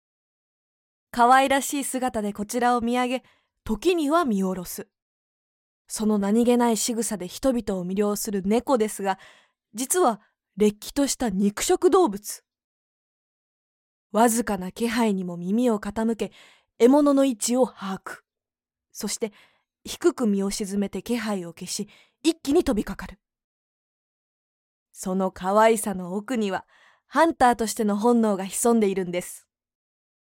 ボイスサンプル
動物ドキュメンタリー風